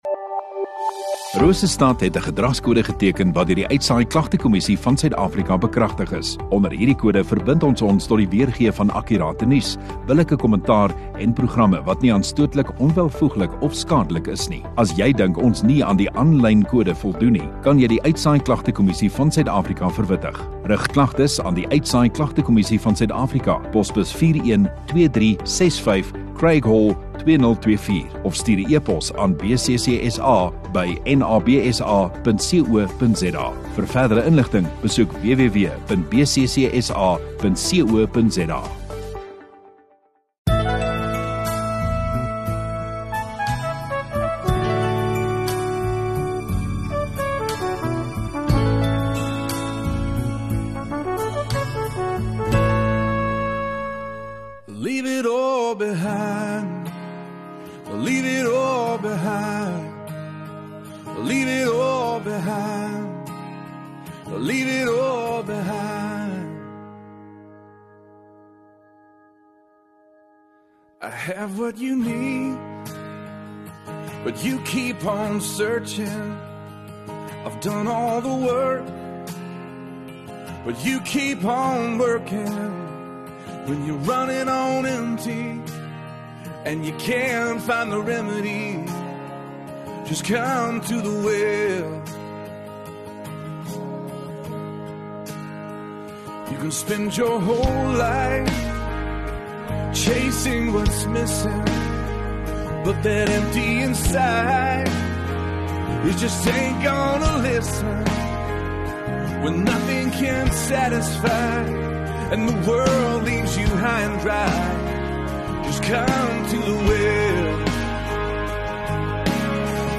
8 Mar Sondagoggend Erediens